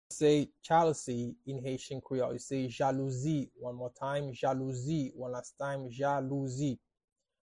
“Jealousy” in Haitian Creole – “Jalouzi” pronunciation by a native Haitian Creole tutor
How-to-say-Jealousy-in-Haitian-Creole-–-Jalouzi-pronunciation-by-a-native-Haitian-Creole-tutor.mp3